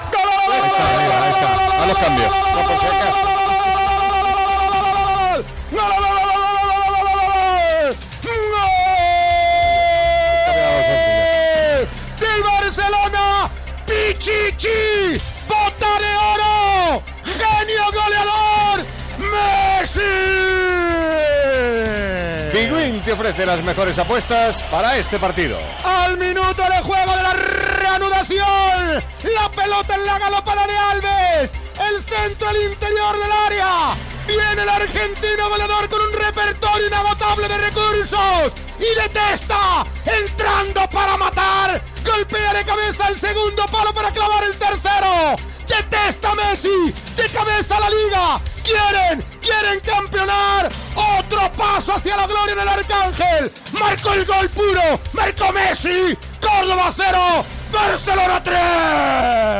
Transmissió del partit de lliga de la primera divisió masculina de futbol entre el Córdoba i el Futbol Club Barcelona.
Narració del gol de Leo Messi.
Esportiu